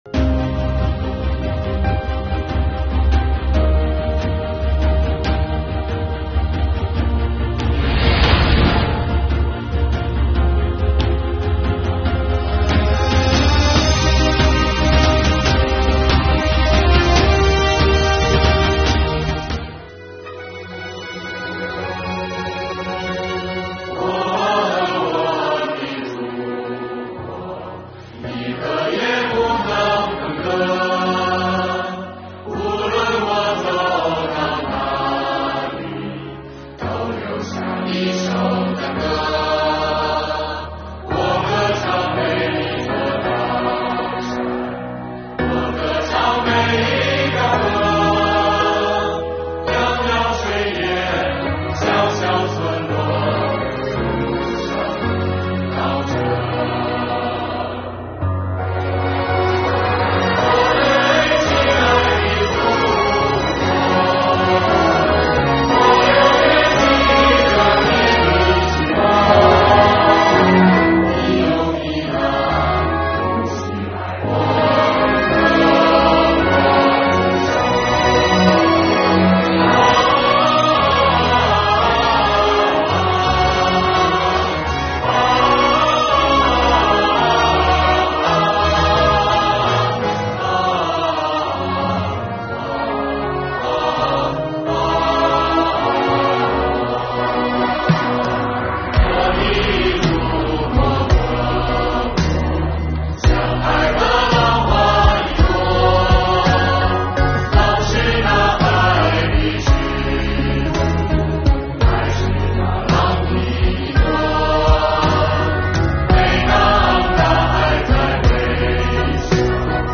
白城市税务系统开展“唱支心歌给党听”活动-我和我的祖国